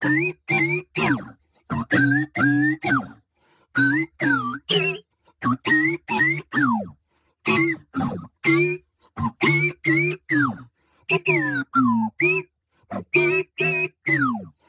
(rhythmic beeps).